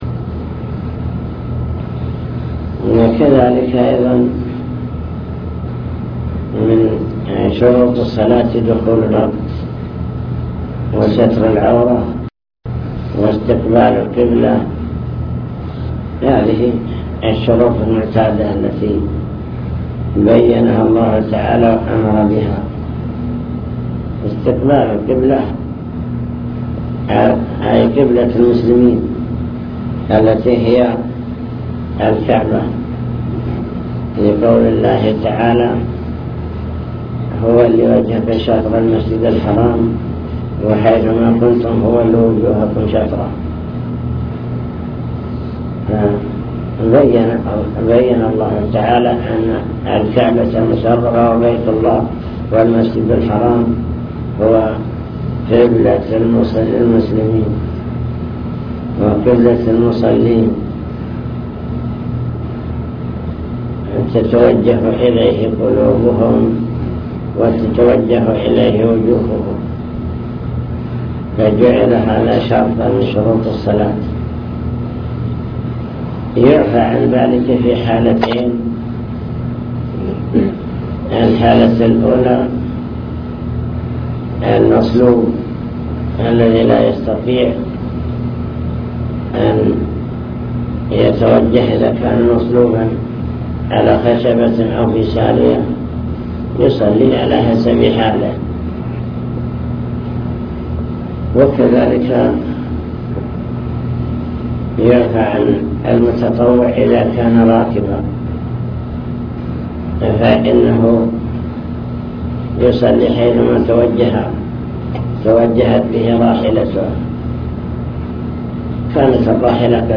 المكتبة الصوتية  تسجيلات - محاضرات ودروس  درس الفجر